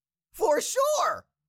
Cartoon Little Monster, Voice, For Sure Sound Effect Download | Gfx Sounds
Cartoon-little-monster-voice-for-sure.mp3